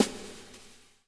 SND DRUMS -L.wav